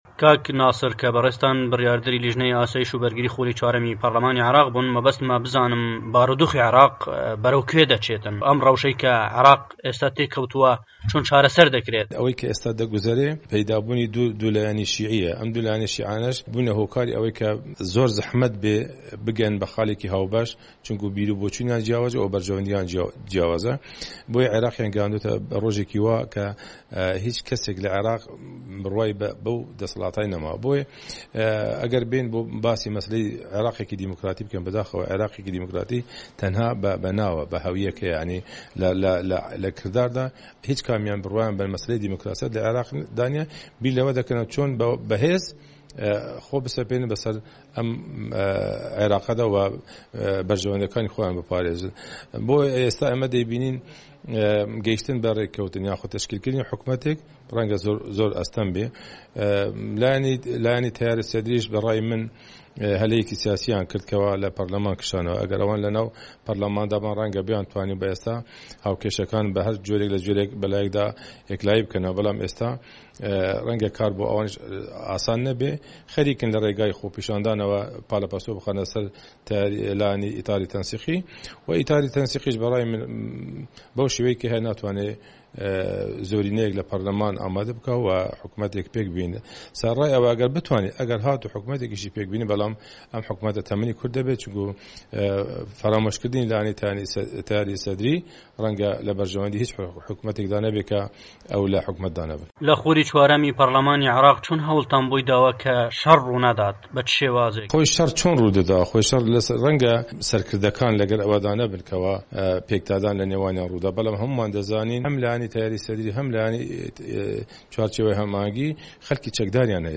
ناسر هەركی بڕیاردەری لیژنەی ئاسایش و بەرگری خولی چوارەمی پەرلەمانی عێراق